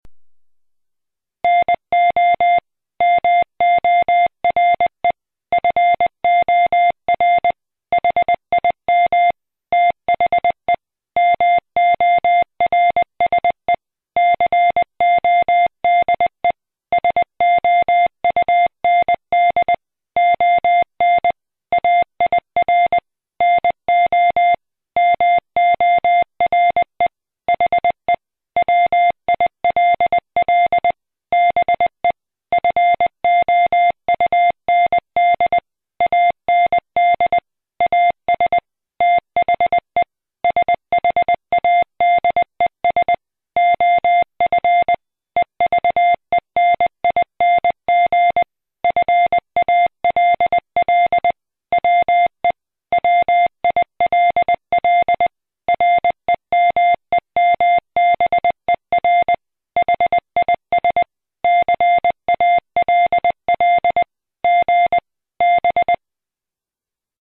MORSE-FAREWELL-REC.mp3